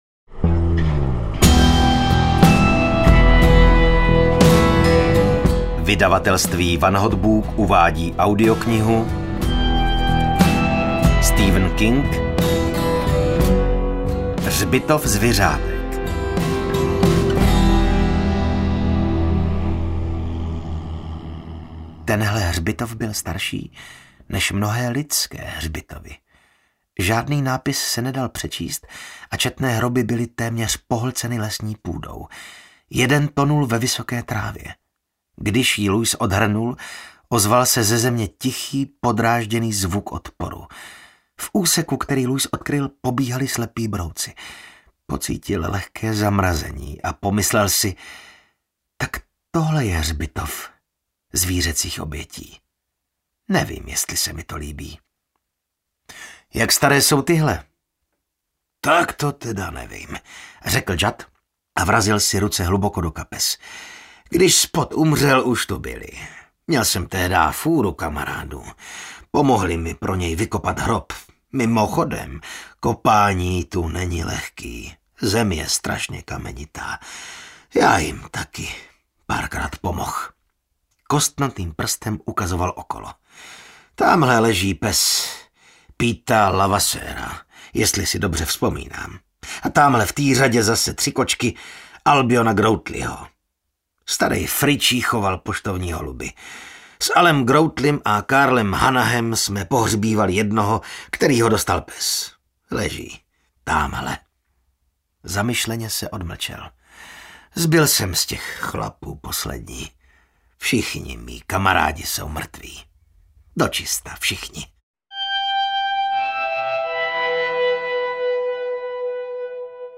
Řbitov zviřátek audiokniha
Ukázka z knihy